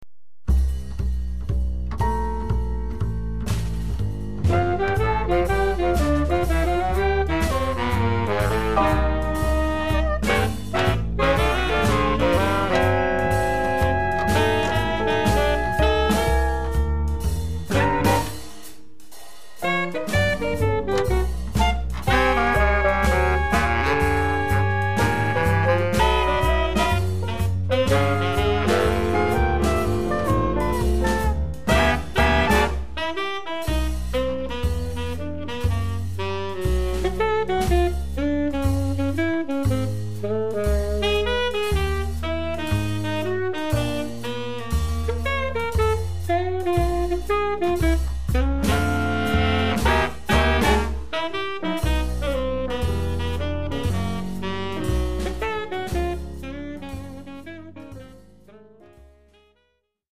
soprano saxophone
alto saxophone
tenor saxophone
baritone saxophone
piano
bass
drums